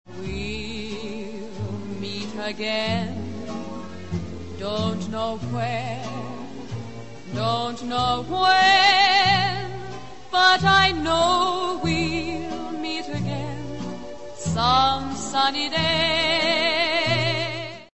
The Allies' wartime sweetheart, Dame Vera Lynn DBE, endorses the Tonsley Time Team